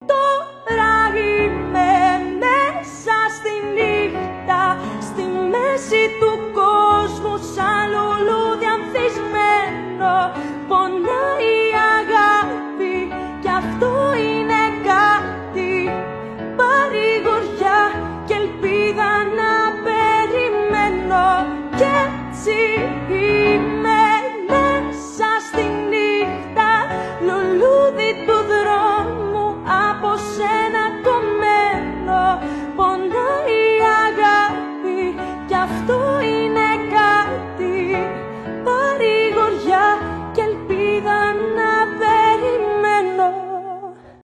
поп , греческие